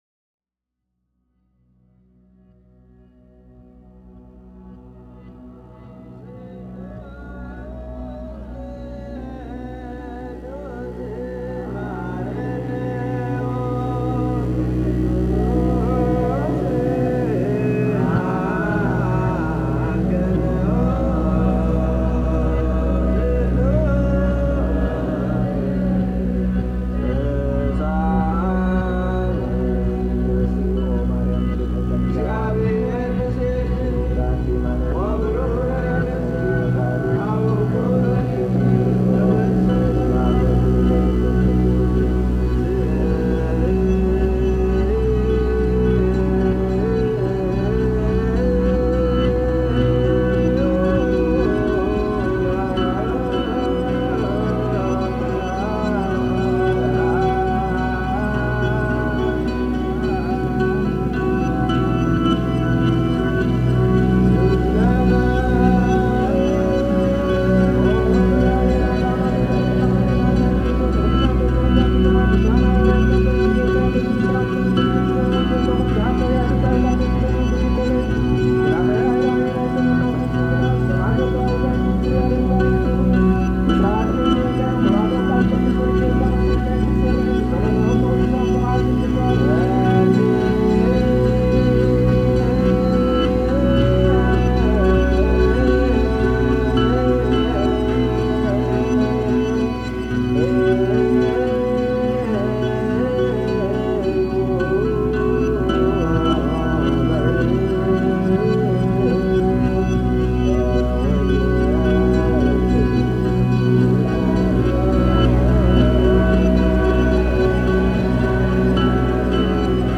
Eritrean church in Rome reimagined